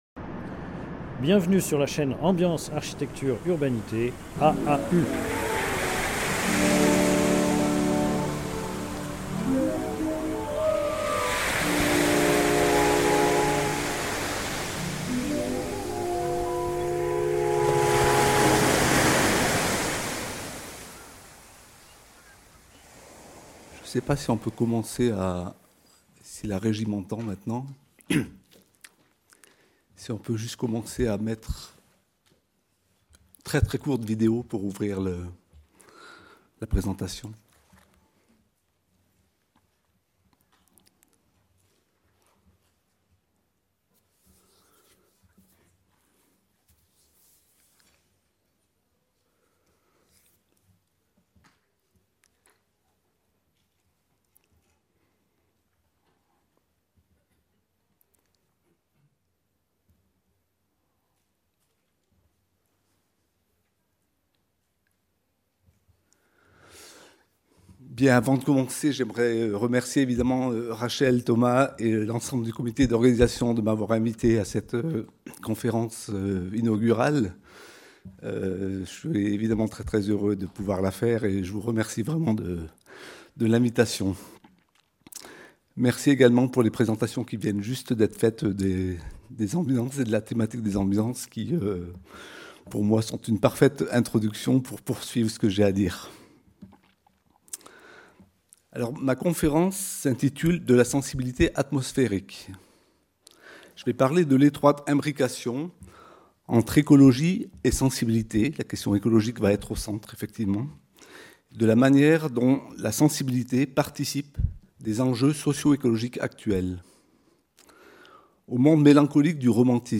Conférence inaugurale du Colloque international Expériences sensibles, fabrique et critique des territoires en mutation